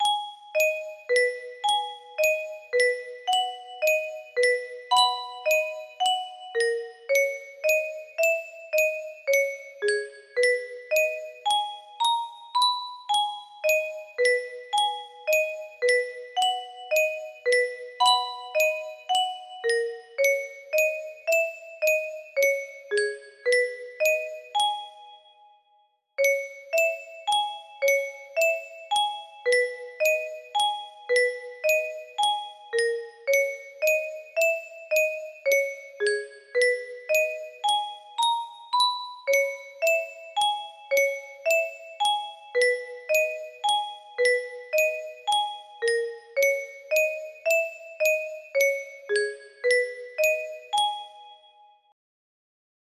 music box-able version (hopefully)